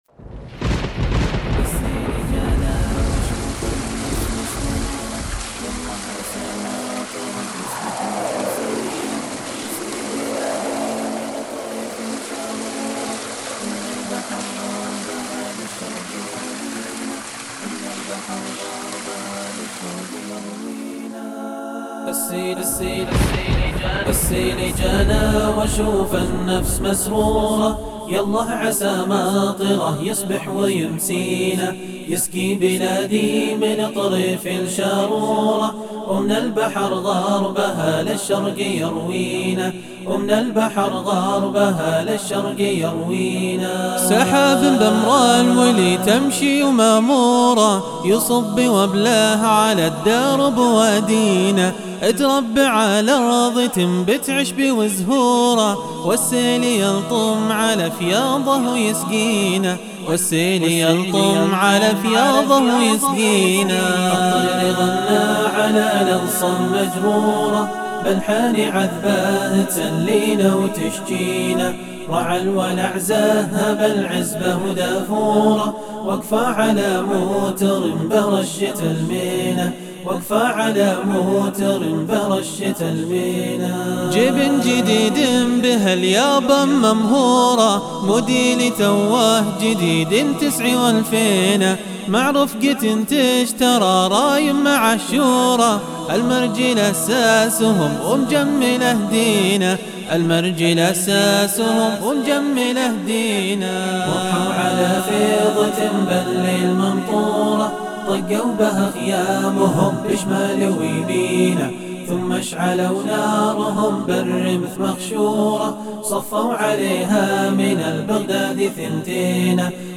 أُنشودة مُتواضعة وليدة الفكرة وسريعة التنفيذ !
وتم تَسجيلها في ستديو لمسات حيث هندستي المتواضعة !